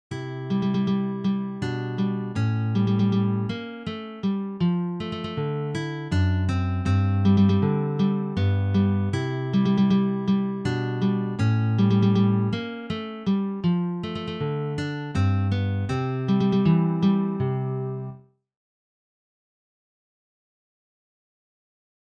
Global
Sololiteratur
Gitarre (1)